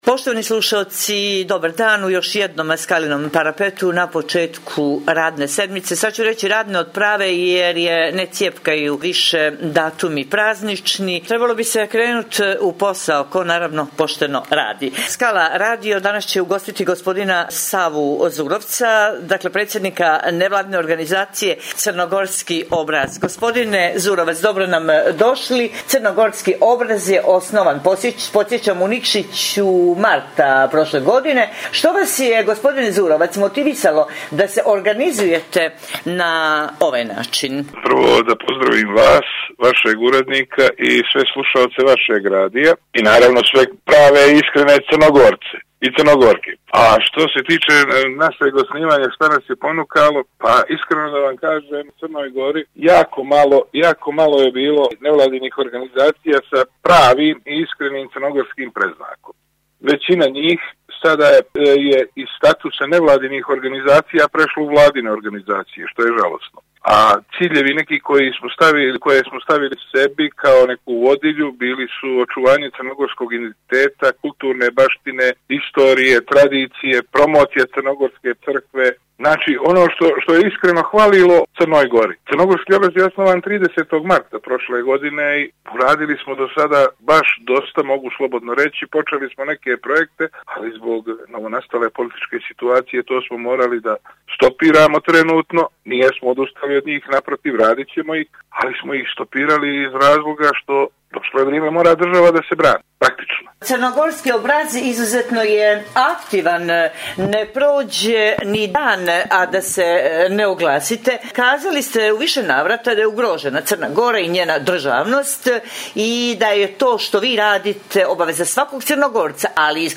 Poštujući pravila nadležnih zbog epidemije korona virusa Skala radio će emisiju “Skalin parapet” emitovati u izmijenjenoj formi i u skraćenom trajanju, budući da nema gostovanja u studiju Skala radija do daljnjeg.
Stoga ćemo razgovore obavljati posredstvom elektronske komunikacije i telefonom, kako bi javnost bila pravovremeno informisana o svemu što cijenimo aktuelnim, preventivnim i edukativnim u danima kada moramo biti doma.